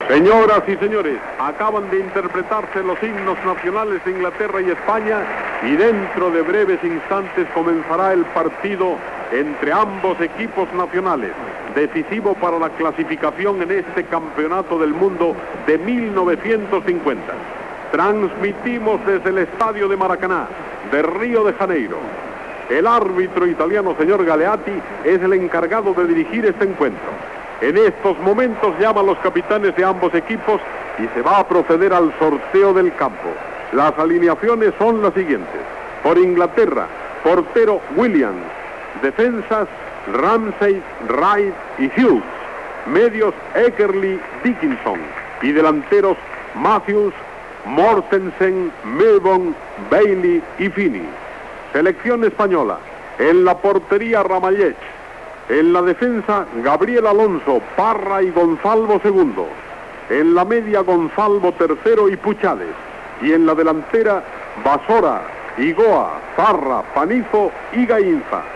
Tres recreacions diferents de la jugada fetes per Matías Prats, en no haver cap enregistrament original.
Esportiu